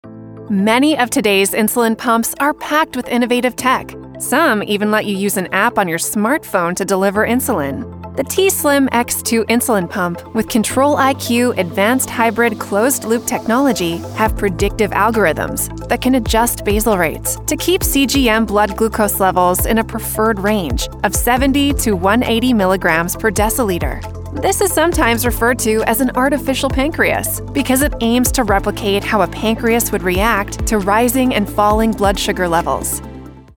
As a female with a warm, approachable, more youthful-sounding voice, I excel at making people feel safe and supported, like a helpful guide.
I’ll record and edit the medical narration from my broadcast-quality studio (you can even live-direct me during this process) and deliver the files to you in the format needed.
• Insulin Pump Medical Explainer - Upbeat, Innovative, Youthful, Energetic